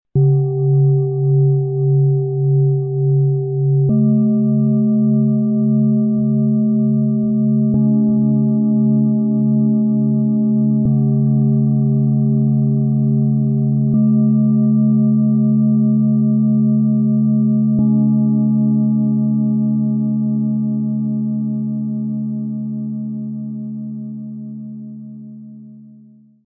Fühle Dich lebendig, selbstsicher und geistig wach - Set aus 3 Planetenschalen, Ø 15,6 - 18,6 cm, 1,94 kg
Im Sound-Player - Jetzt reinhören kannst Du den Original-Klang genau dieser Schalen erleben – eine Mischung aus Kraft, Herzenswärme und geistiger Klarheit.
Mit dem mitgelieferten Klöppel bringst Du das Set sanft zum Klingen. Seine weichen Schwingungen entfalten sich mühelos.
Tiefster Ton: Tageston
Mittlerer Ton: Eros
Höchster Ton: Merkur & Uranus
MaterialBronze